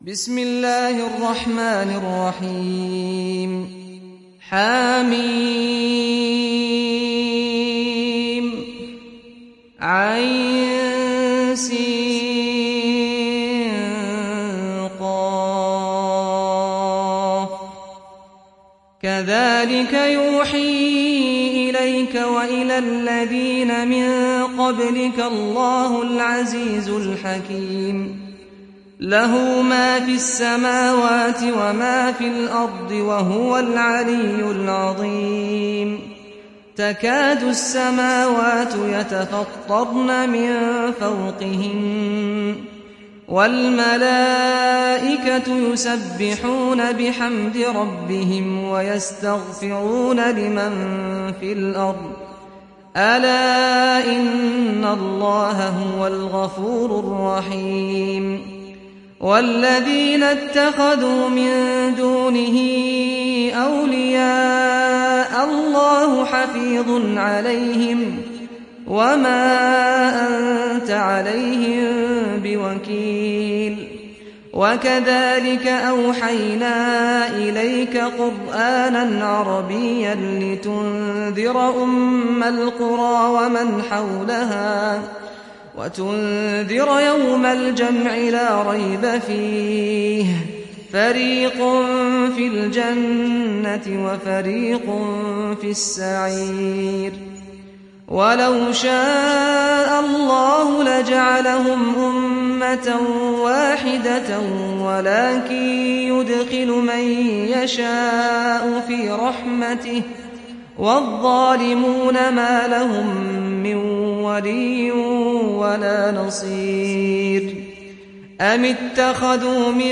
تحميل سورة الشورى mp3 بصوت سعد الغامدي برواية حفص عن عاصم, تحميل استماع القرآن الكريم على الجوال mp3 كاملا بروابط مباشرة وسريعة